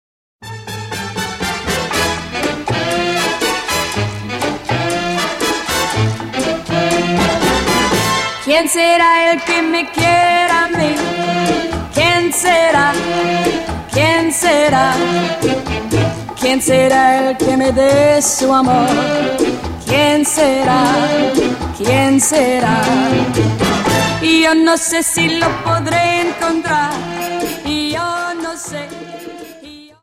Dance: Cha Cha 30